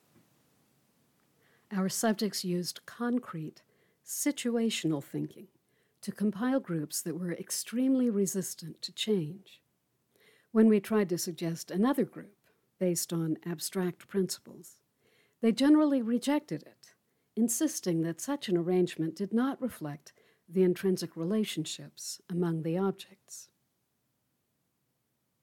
Here are a short recording from my H2 and my desktop C-1U, of the same 2 sentences.
A new complication is that it’s a hot afternoon and the attic fan is going, right above my office. I left a little room-noise and the beginning and end of each recording in hopes it could be discounted; if not, I can record again tonight once it cools off.